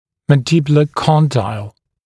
[ˌmæn’dɪbjulə ‘kɔndaɪl][ˌмэн’дибйулэ ‘кондайл]нижнечелюстной мыщелок